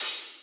DDW6 OPEN HAT 4.wav